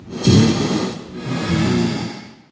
breathe4.ogg